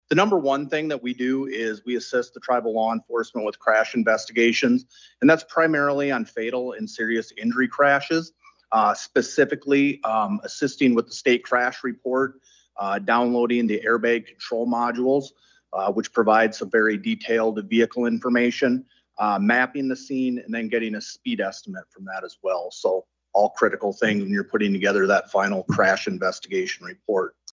AGENCY VILLAGE, S.D.(HubCityRadio)- On Thursday, the first meeting of the Interim State Tribal Relations Committee was held at the Sisseton-Wahpeton Oyate Headquarters at Agency Village.